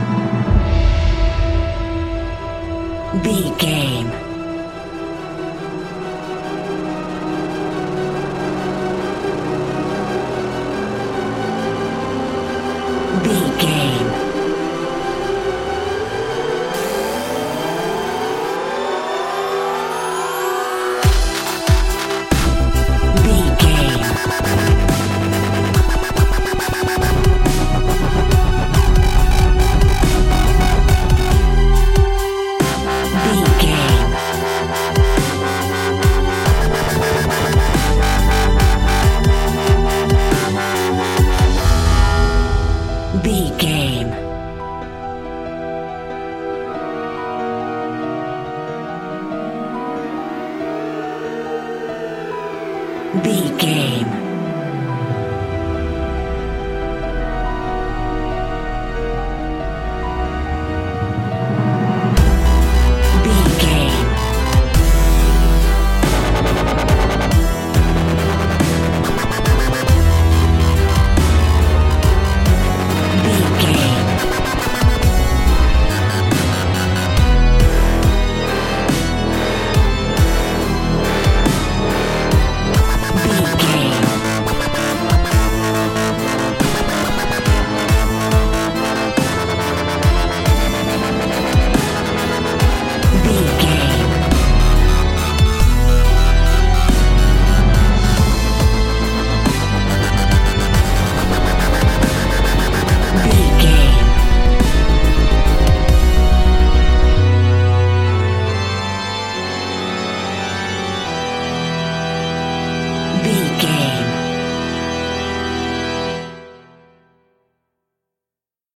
Aeolian/Minor
Slow
strings
drums
drum machine
synthesiser
piano
electric piano
orchestral
orchestral hybrid
dubstep
aggressive
energetic
intense
bass
synth effects
wobbles
driving drum beat
epic